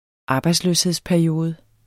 Udtale [ ˈɑːbɑjdsˌløːsheðs- ]